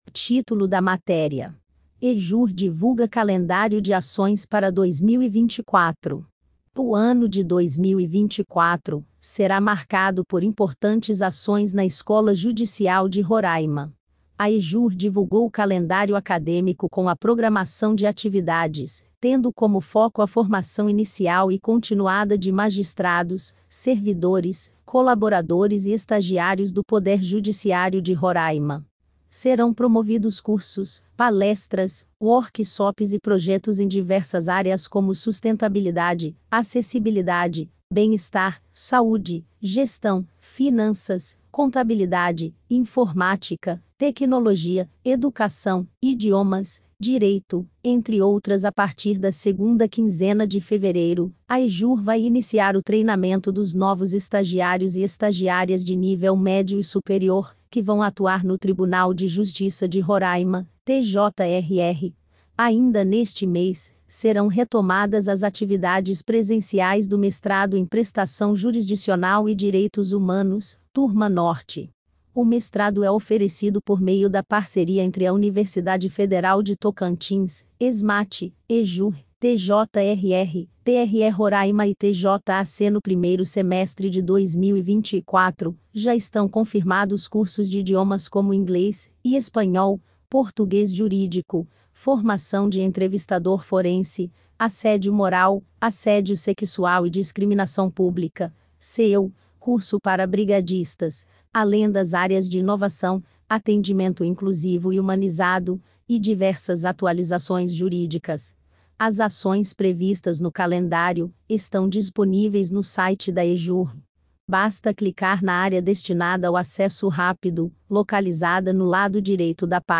Acompanhe a matéria tambem em audio
EJURR_CALENDARIO_IA.mp3